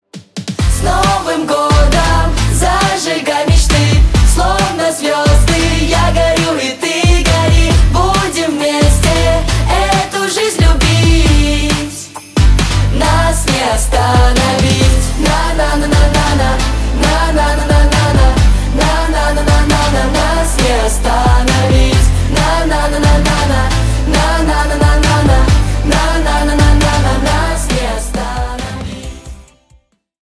Поп Музыка
новогодние # весёлые